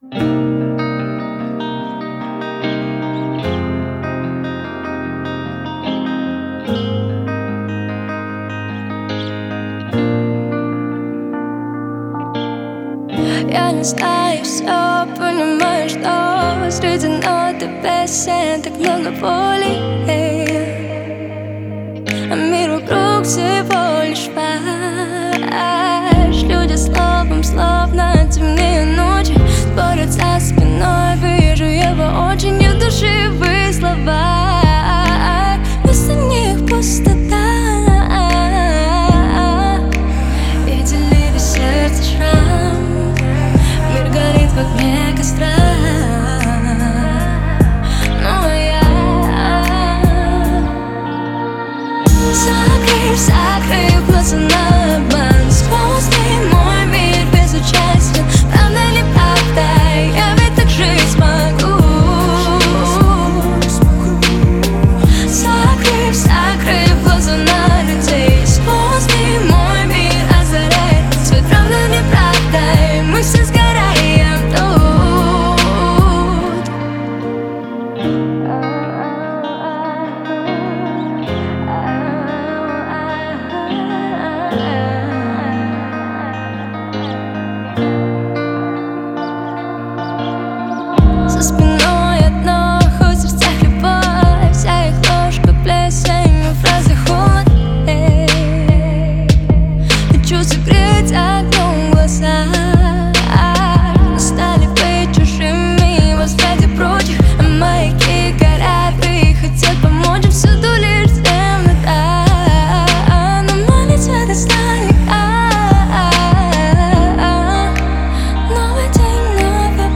это трек в жанре поп-рок с элементами электронной музыки